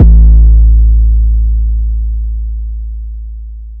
MB 808 (30).wav